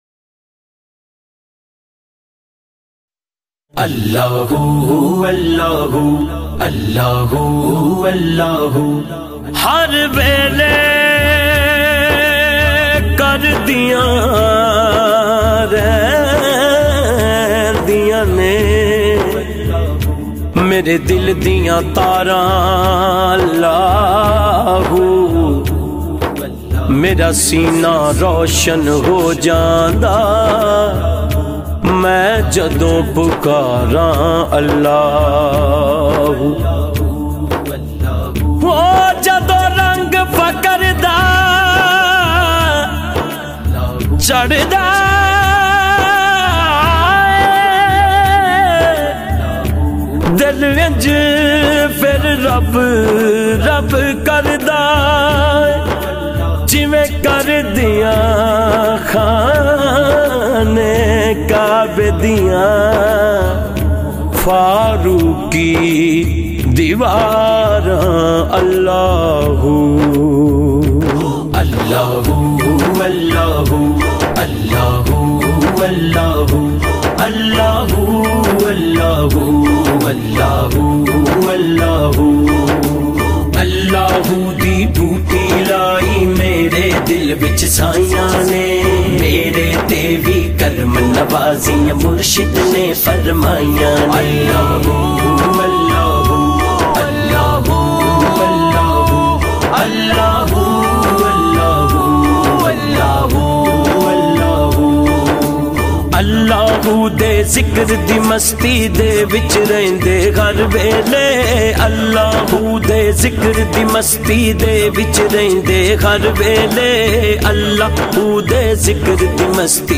melodic voice